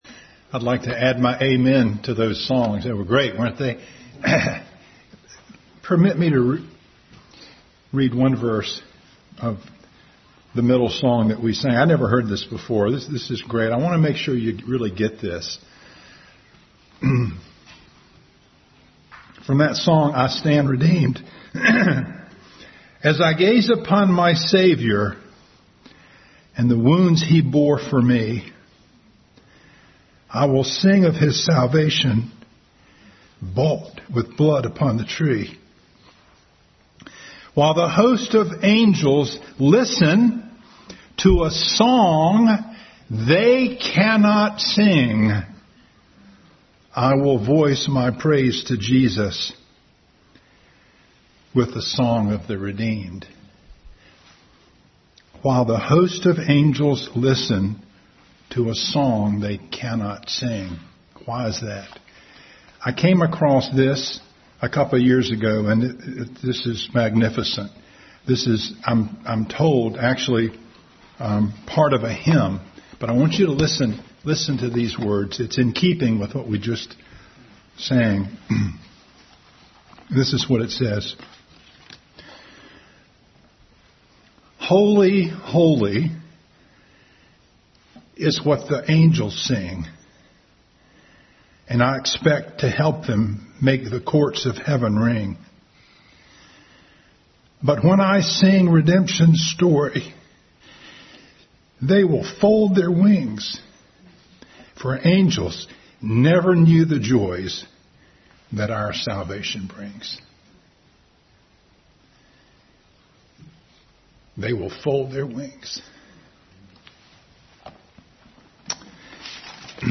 Bible Text: 2 Samuel 3:39, 1 Chronicles 2:12-17, 2 Samuel 2:18-23, 21:16-17, 23:13-19, 24:1-9, 3:22-27, 18:2-18, 1 Chronicles 11:4-7, 1 Kings 1:5 | Family Bible Hour Message.